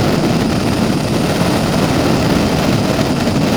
rpg_rocket_loop.wav